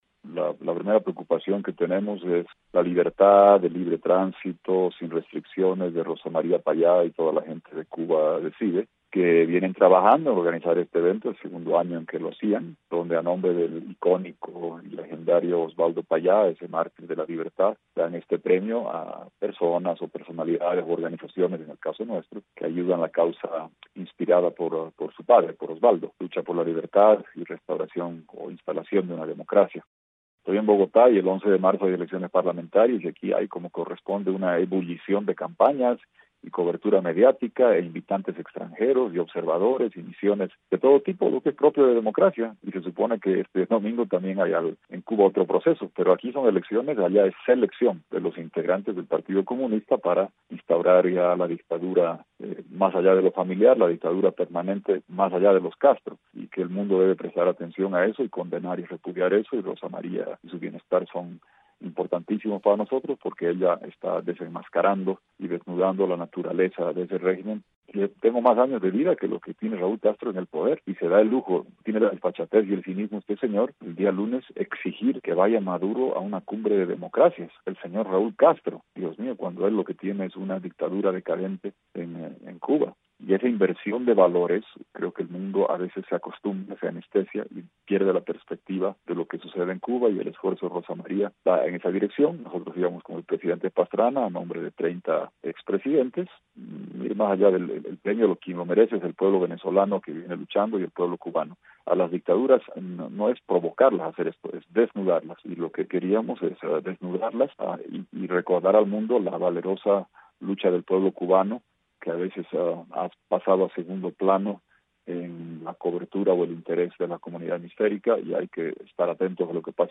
En entrevista telefónica con nuestro colega Tomás Regalado desde Bogotá, el ex presidente de Bolivia Jorge Quiroga se refirió a su expulsión el miércoles de Cuba junto con su colega colombiano Andrés Pastrana.